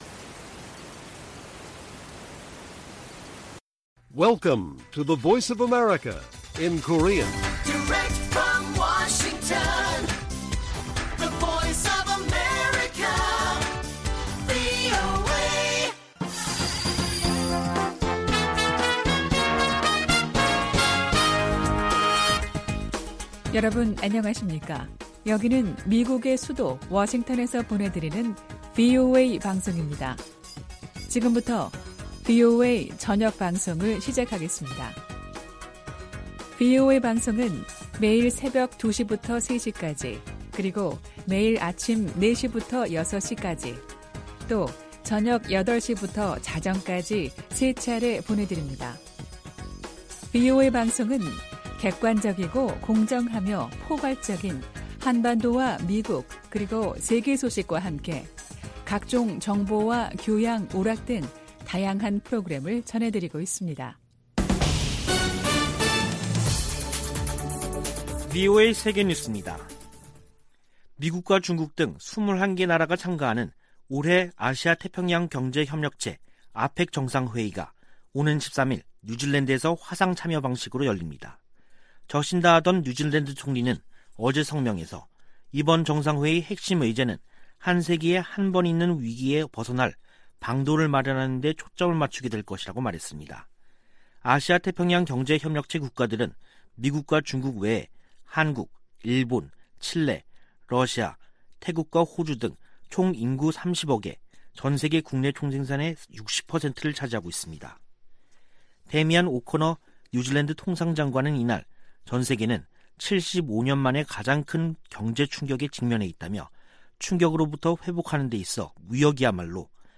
VOA 한국어 간판 뉴스 프로그램 '뉴스 투데이', 2021년 11월 9일 1부 방송입니다. 미국 민주당 상·하원 의원들이 대북 인도적 지원을 위한 규정 완화를 촉구하는 서한을 조 바이든 대통령에게 보냈습니다. 일본은 북 핵 위협 제거되지 않은 상태에서의 종전선언을 우려하고 있을 것으로 미국의 전문가들이 보고 있습니다. 최근 북-중 교역이 크게 증가한 가운데 코로나 사태 이후 처음으로 열차가 통행한 것으로 알려졌습니다.